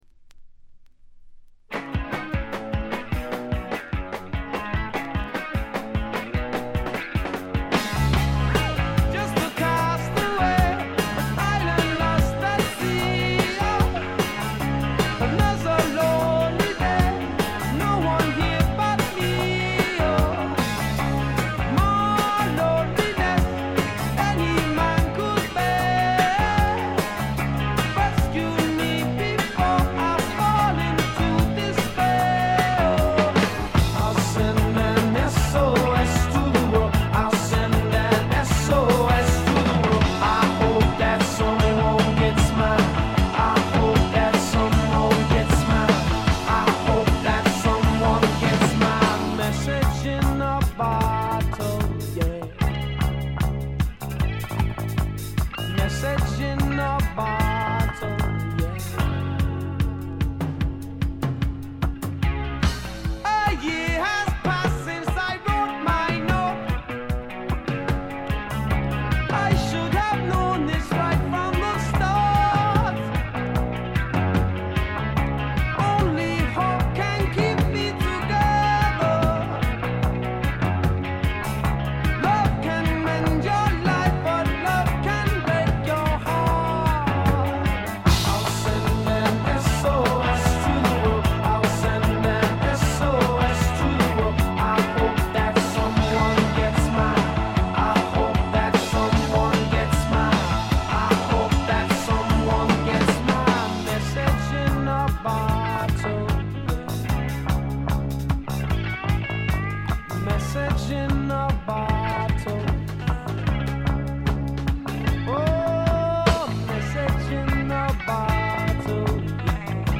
これ以外はほとんどノイズ感無し。
試聴曲は現品からの取り込み音源です。
Recorded At - Surrey Sound Studios